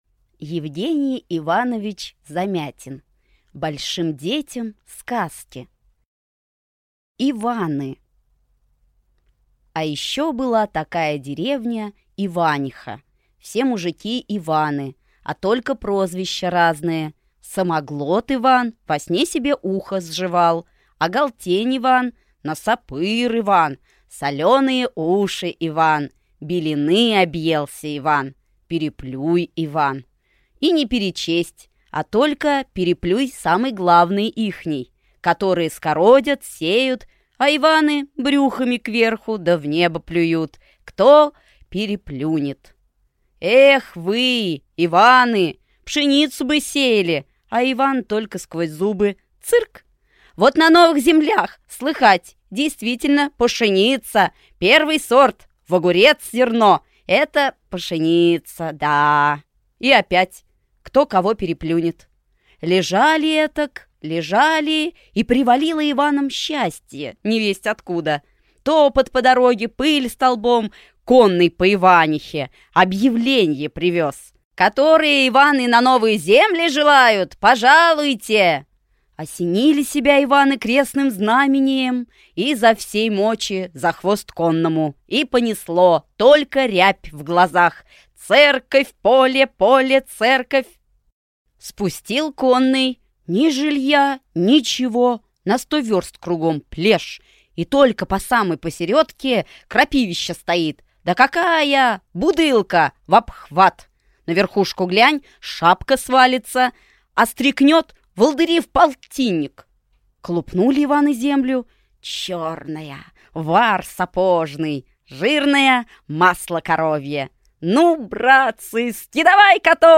Аудиокнига Большим детям сказки | Библиотека аудиокниг
Прослушать и бесплатно скачать фрагмент аудиокниги